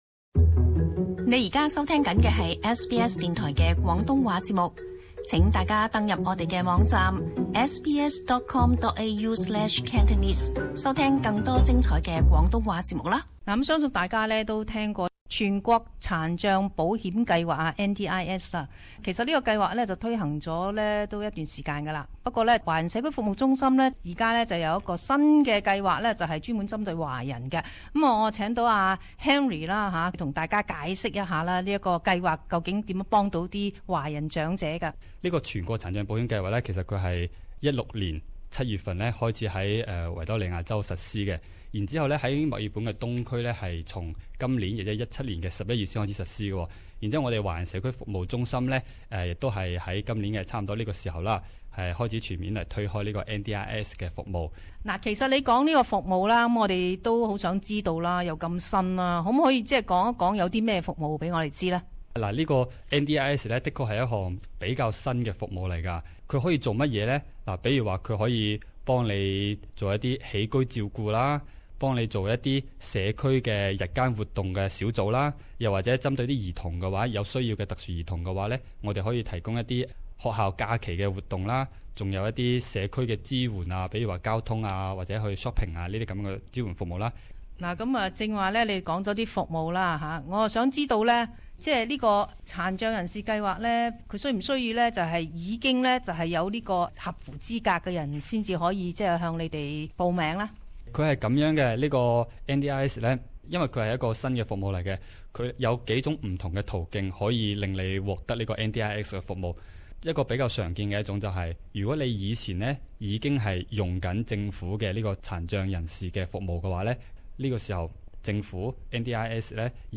【社团专访】残疾华人服务项目